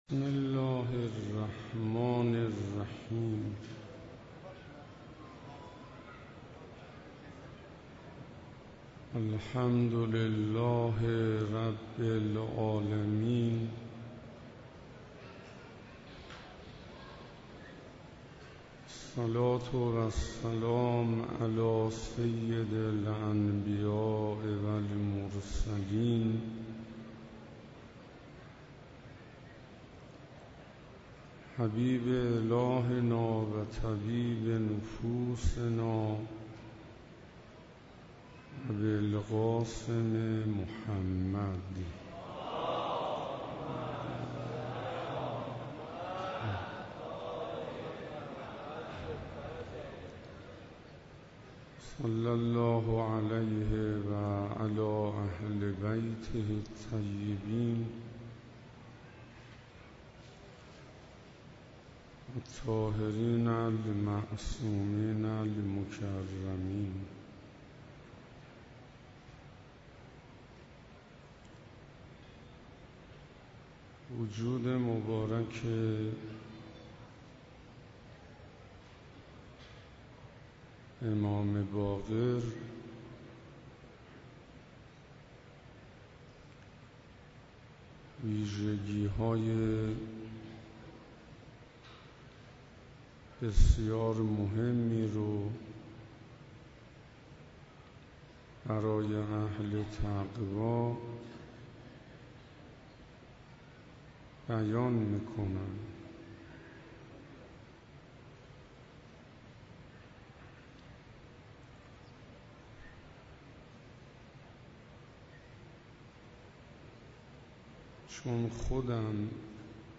شب سوم - شهادت امام باقر علیه السلام 97 - مسجد اعظم قم